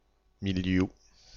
Ääntäminen
Ääntäminen Tuntematon aksentti: IPA: /mil.dju/ Haettu sana löytyi näillä lähdekielillä: ranska Käännös Ääninäyte Substantiivit 1. mildew 2. mold US Suku: m .